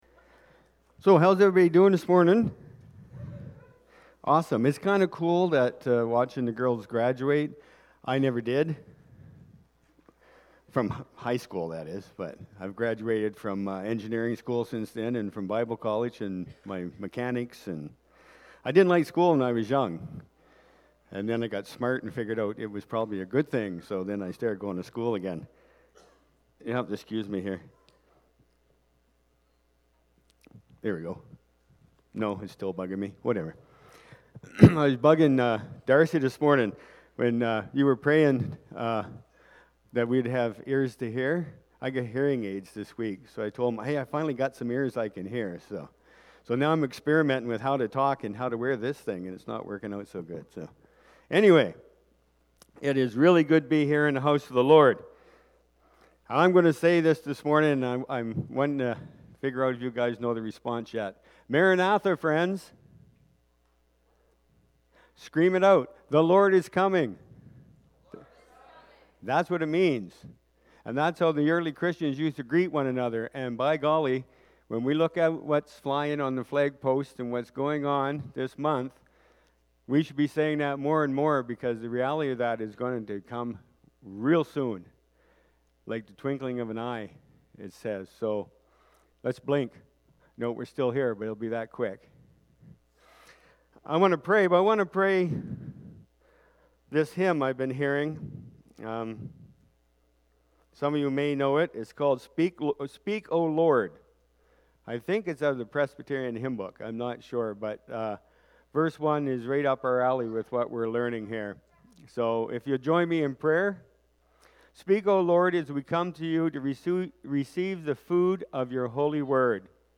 June-4-sermon-audio.mp3